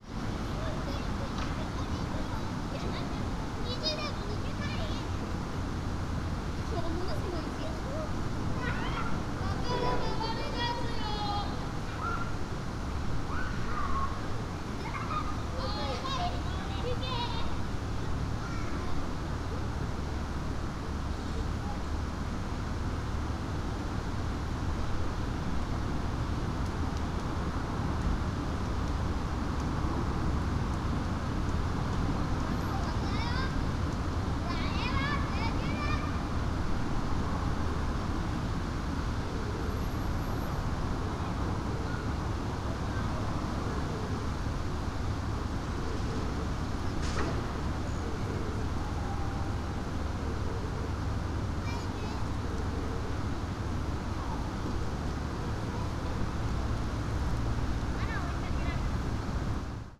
Fukushima Soundscape: Shinhama Park